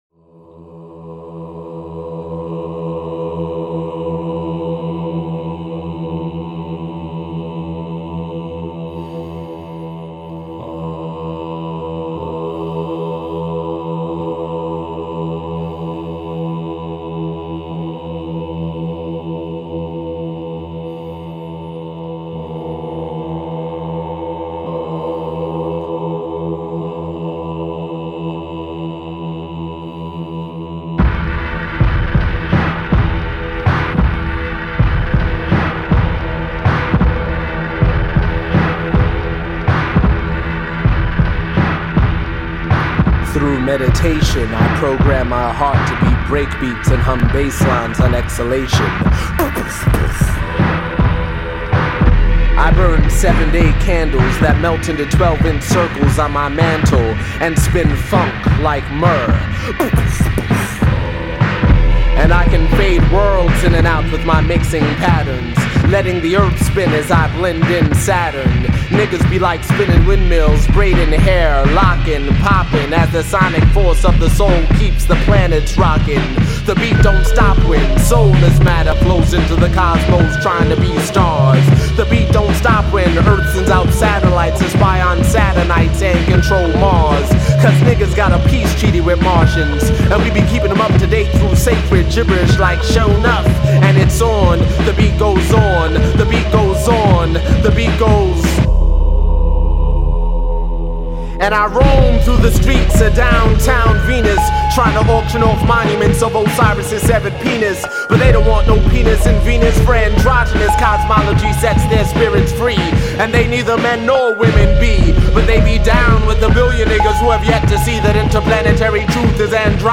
Live Spoken Word - Click on the Audio Links Below: